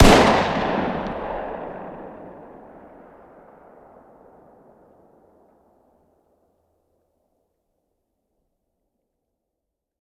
fire-dist-10x25-pist..>2024-09-10 22:10 504K